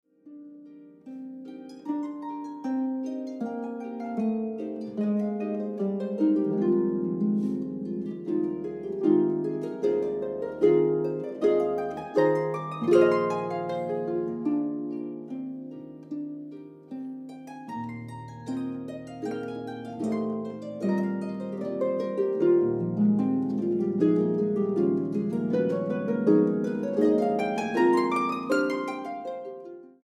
Dos arpas
arpista
Boonkker Audio Tacubaya, Ciudad de México.